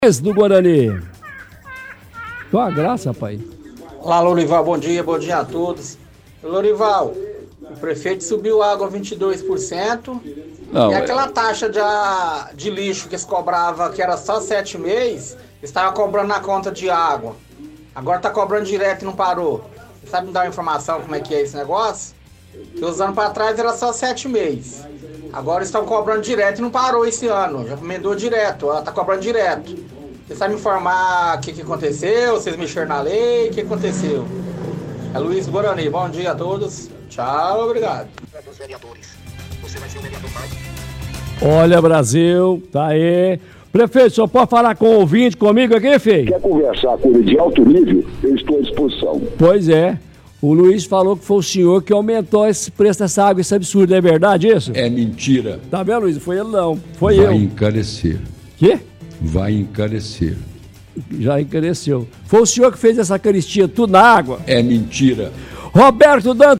– Ouvinte questiona sobre o valor da água cobrada.